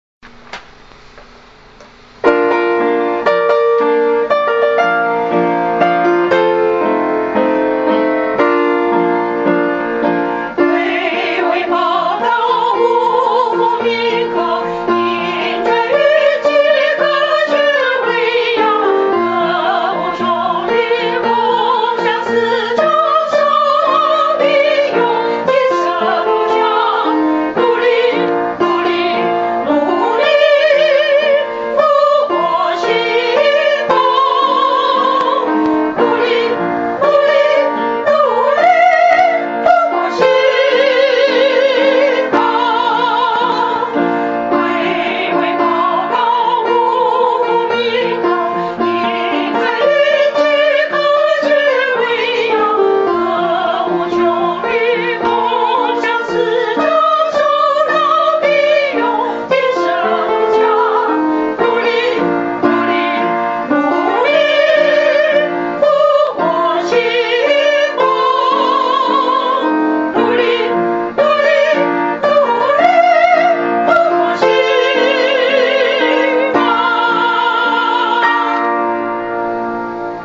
校歌示範帶